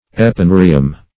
Search Result for " epineurium" : The Collaborative International Dictionary of English v.0.48: Epineurium \Ep`i*neu"ri*um\, n. [NL., fr. Gr.